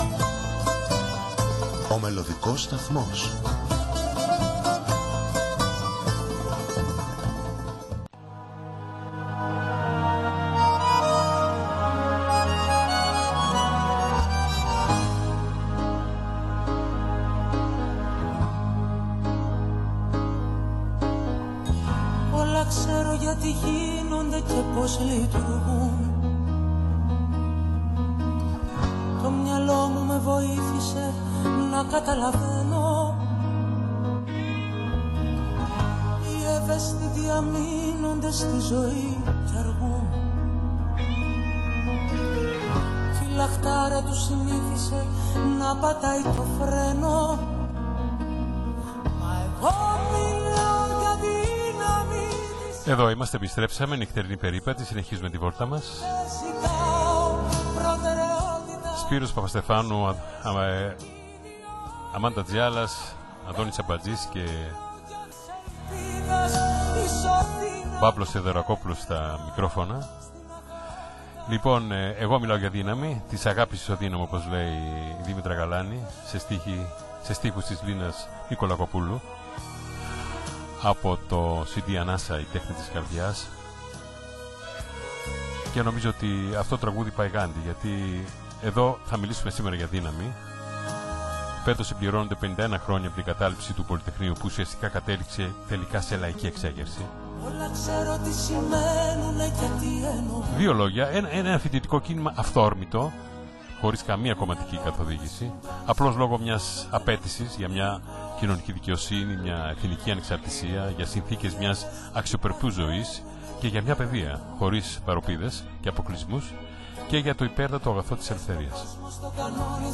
μιλάει σε ζωντανή σύνδεση